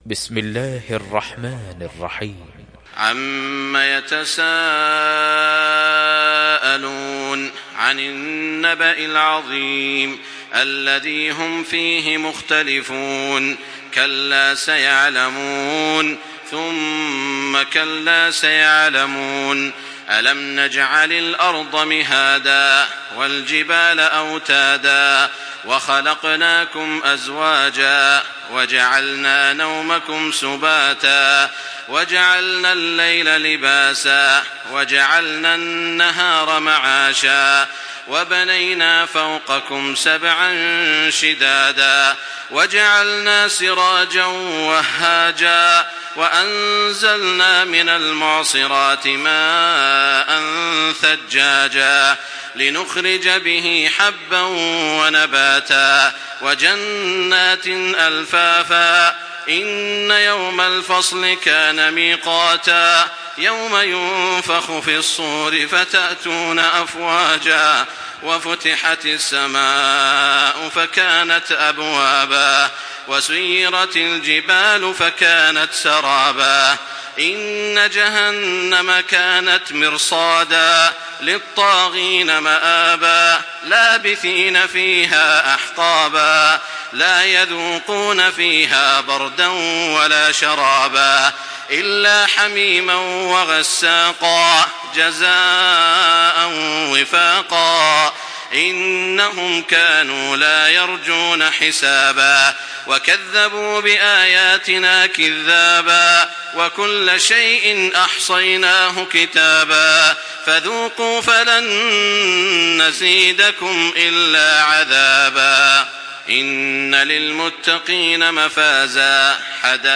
Surah An-Naba MP3 by Makkah Taraweeh 1425 in Hafs An Asim narration.
Murattal Hafs An Asim